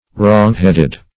Wrongheaded \Wrong"head`ed\, a.